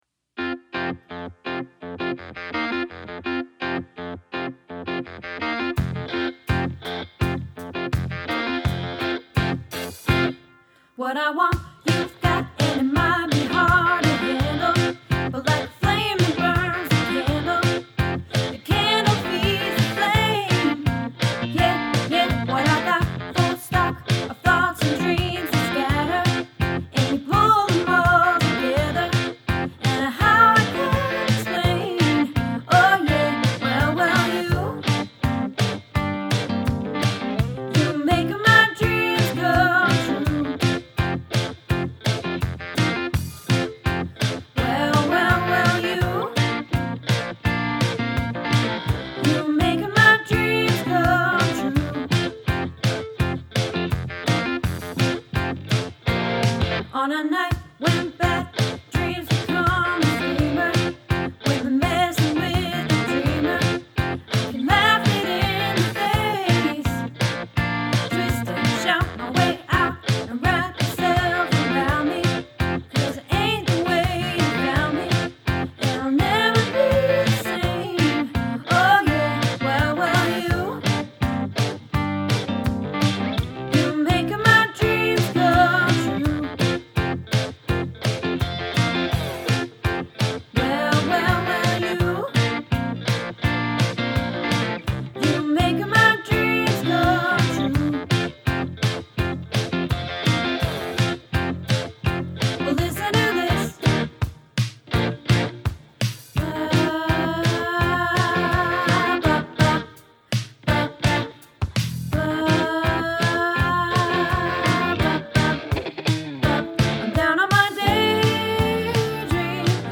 You Make My Dreams – Alto | Happy Harmony Choir